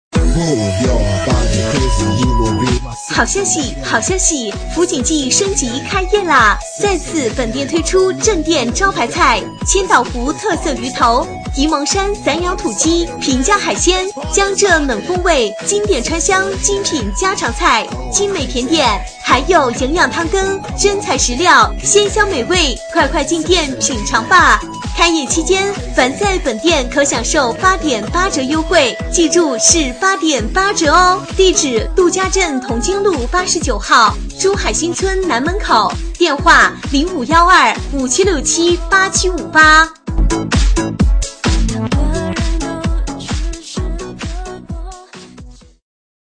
【女23号促销】福锦记
【女23号促销】福锦记.mp3